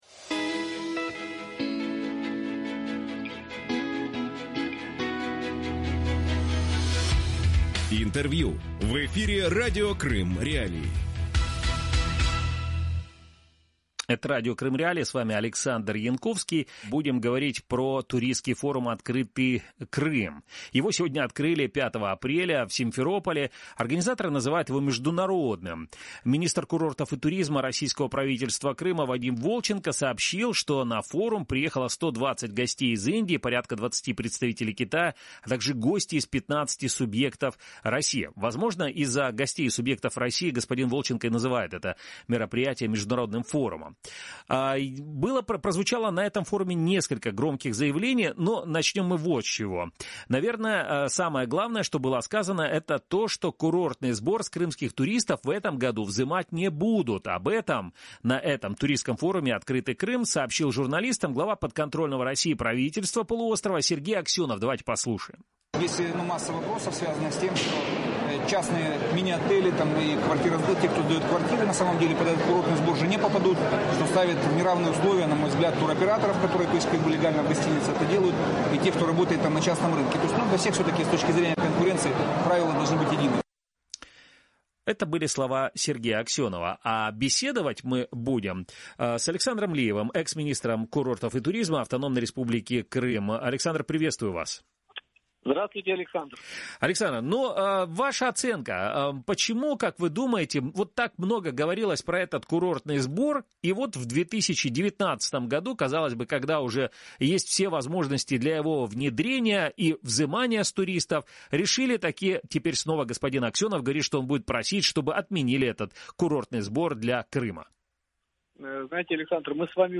Курортная бесперспектива Крыма. Интервью с Александром Лиевым